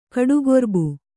♪ kaḍugorbu